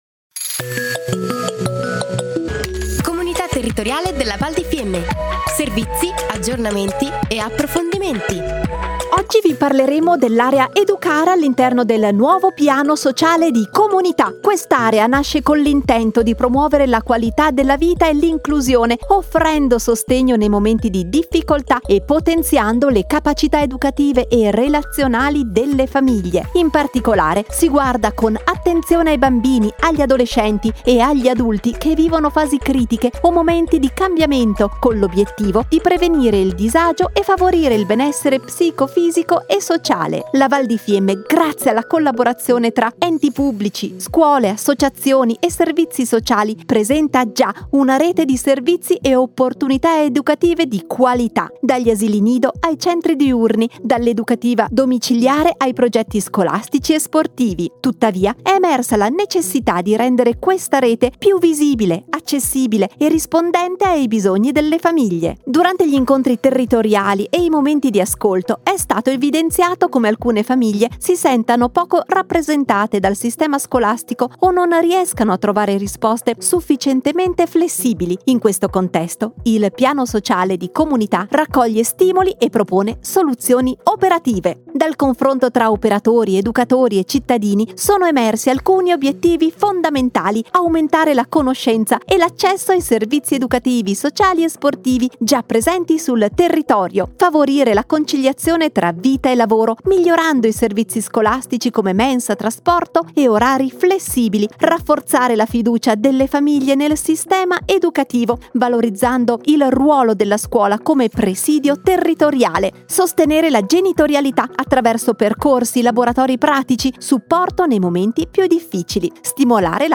Puntata nr. 7 - Comunità Piano Sociale - Area Educare / Anno 2025 / Interviste / La Comunità si presenta tramite Radio Fiemme / Aree Tematiche / Comunità Territoriale della Val di Fiemme - Comunità Territoriale della Val di Fiemme